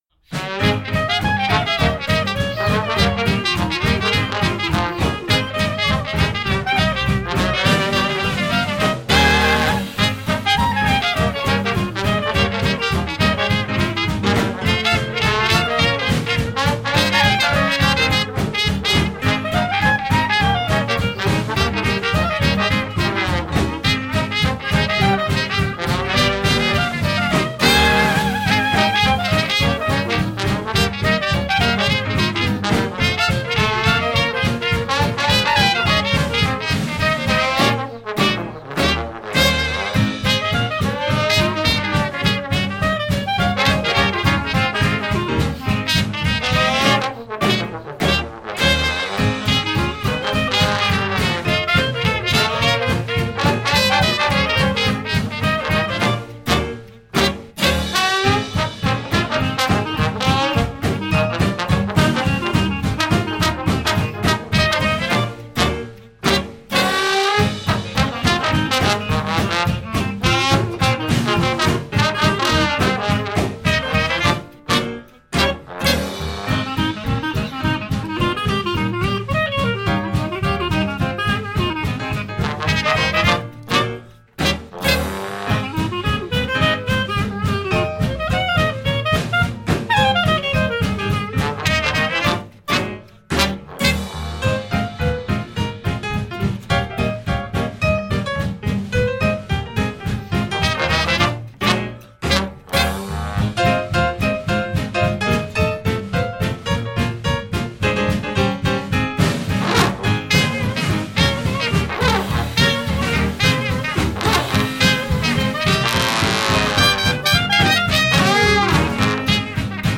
cornet
trombone
clarinette
piano
guitare, banjo, chant
contrebasse
batterie